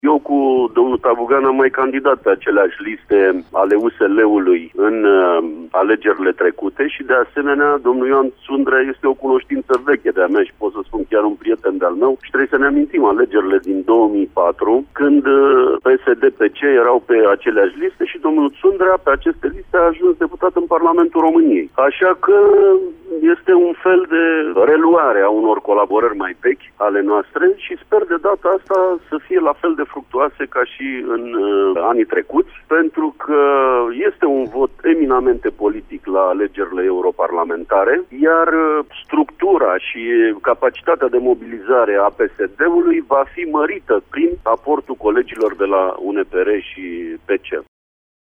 Deputatul Ion Mocioalcă a declarat pentru postul nostru de radio că USD nu reprezintă o formulă artificială în judeţ: